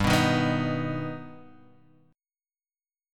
G+ chord